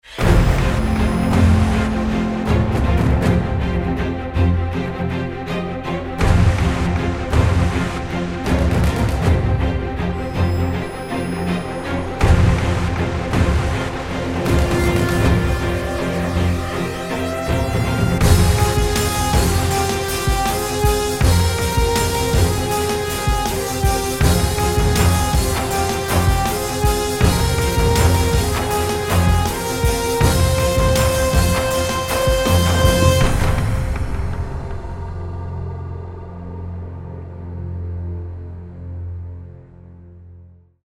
exhilarating score music tracks